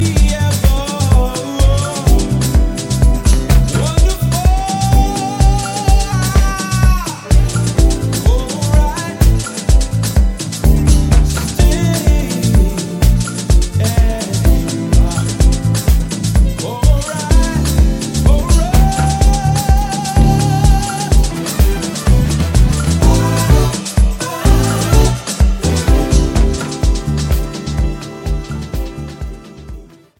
REMIX TRACKS
Vocal Mix